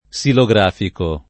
vai all'elenco alfabetico delle voci ingrandisci il carattere 100% rimpicciolisci il carattere stampa invia tramite posta elettronica codividi su Facebook silografico [ S ilo g r # fiko ] o xilografico [ k S ilo g r # fiko ] agg.; pl. m. ‑ci